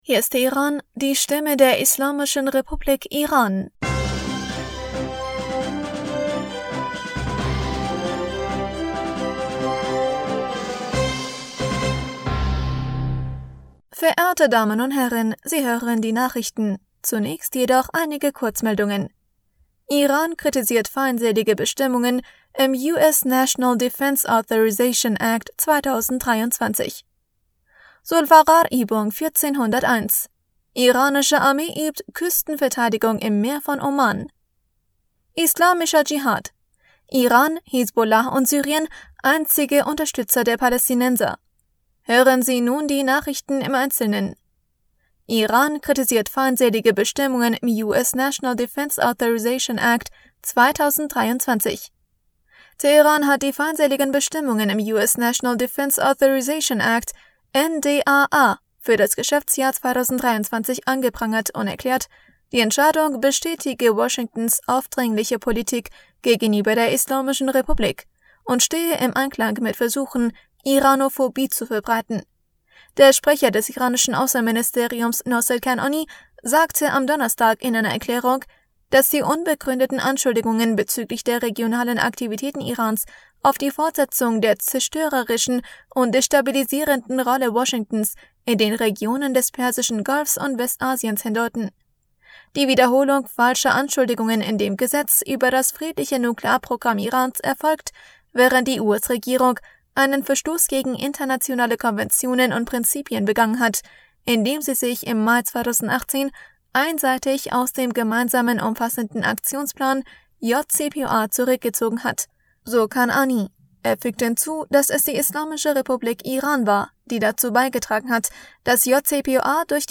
Nachrichten vom 30. Dezember 2022